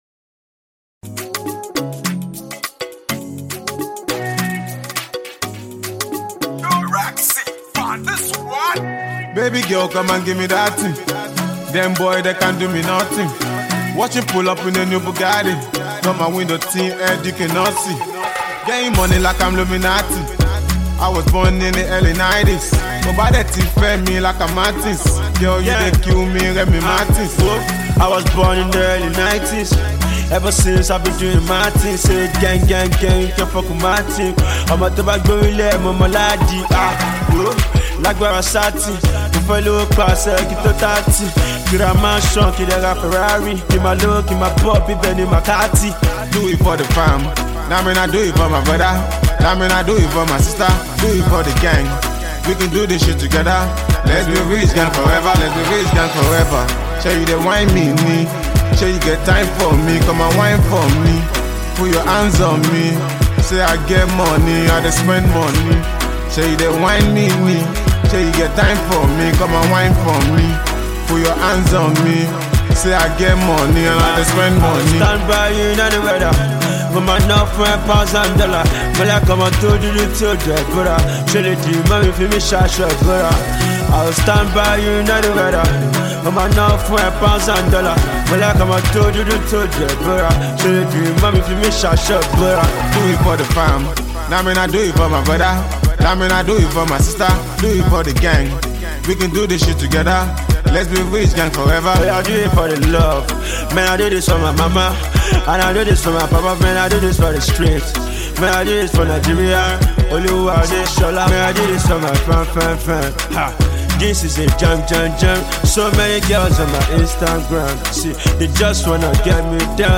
UK Based Nigerian singer
catchy mid-tempo cut